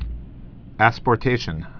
(ăspôr-tāshən)